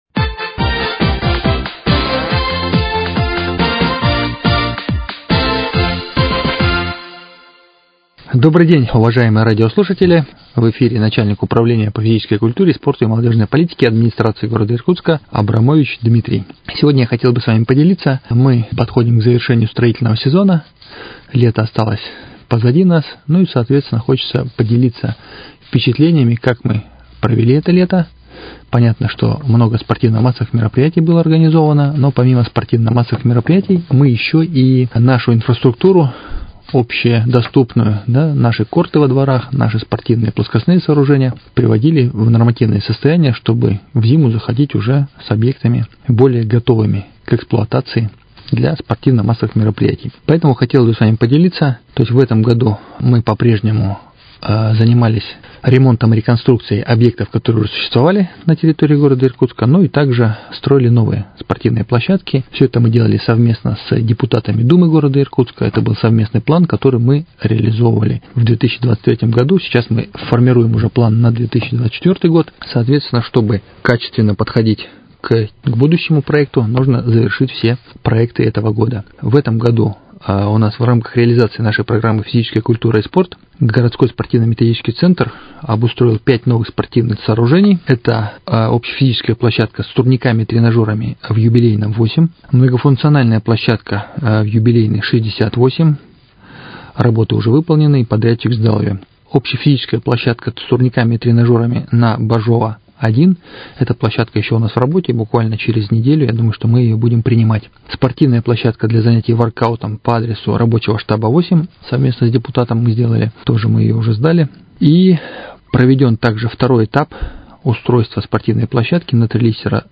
Отчёт за определённый период о благоустройстве спортивных объектов в нашем городе. Выступление Дмитрия Абрамовича - начальника управления по физической культуре, спорту и молодёжной политике Администрации г. Иркутска Комитета по социальной политике и культуре.